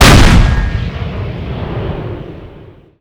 Pack de Efeitos e Transições / Efeitos sonoros SFX / War.Machines.Sound.Effects.Pack / flak aka motar Outros WAV flak88_fire02.wav cloud_download WAV flak88_fire03.wav cloud_download